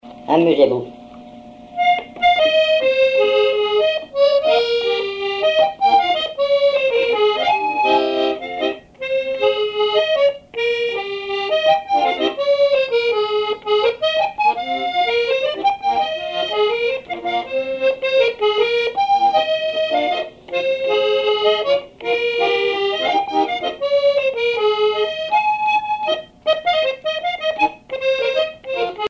accordéon(s), accordéoniste
Répertoire à l'accordéon chromatique
Pièce musicale inédite